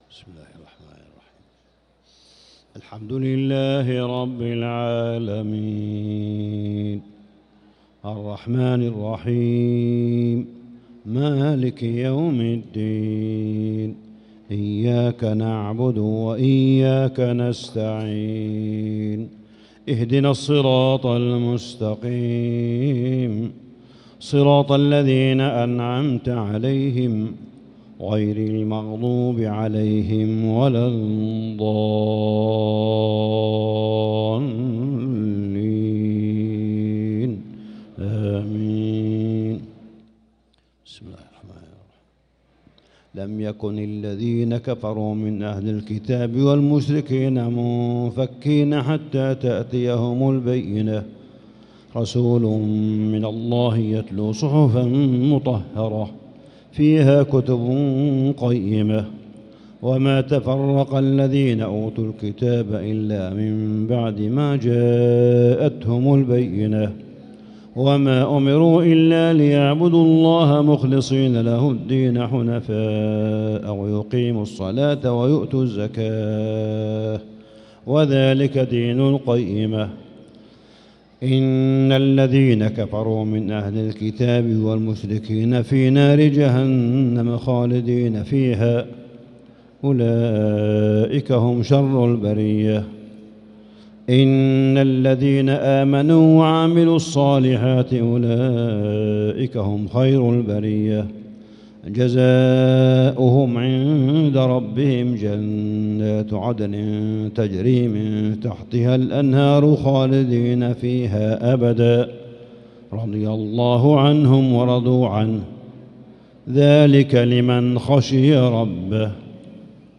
صلاة العشاء للقارئ صالح بن حميد 12 رمضان 1445 هـ
تِلَاوَات الْحَرَمَيْن .